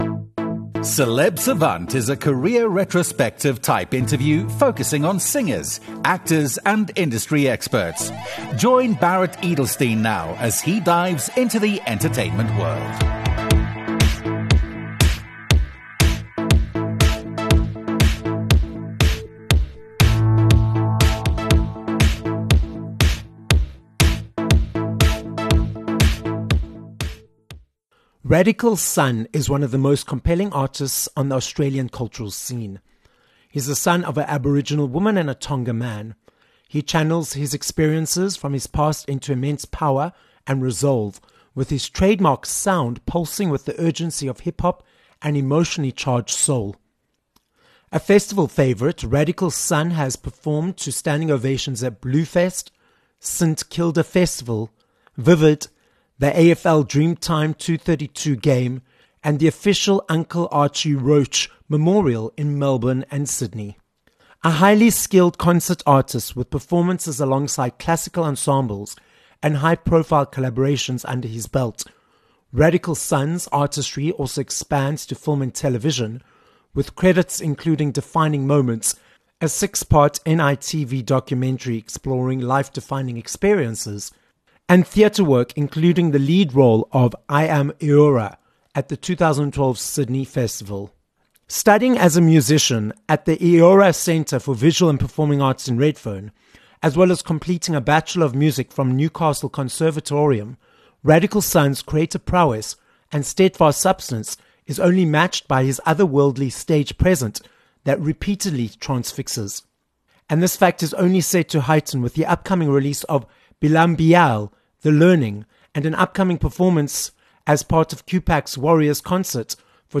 Interval